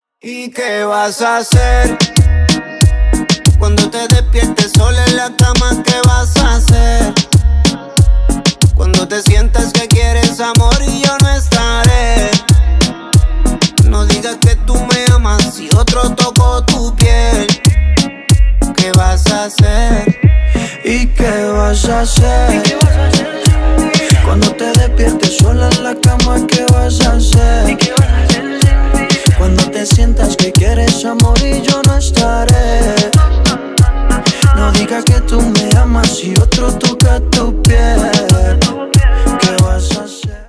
латинские